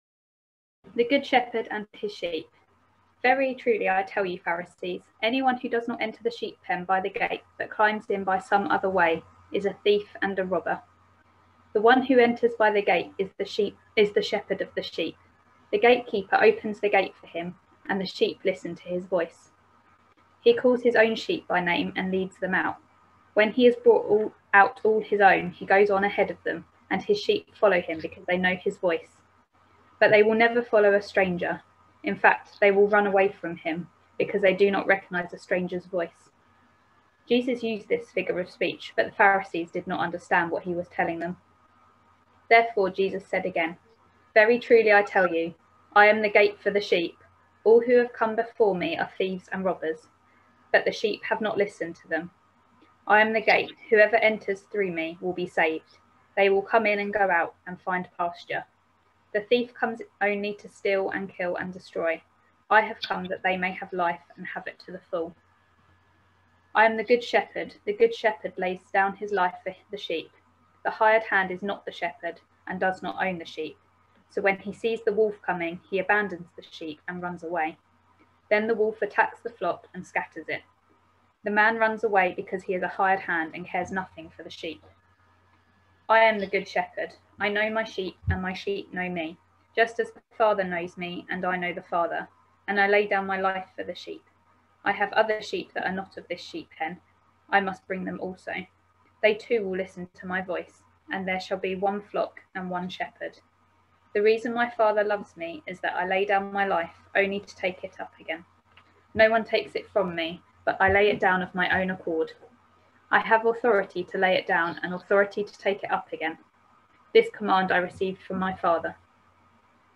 A sermon preached on 14th March, 2021, as part of our John series.